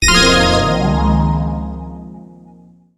Arcade - Taiko no Tatsujin 2020 Version - Common Sound Effects
Service Switch.wav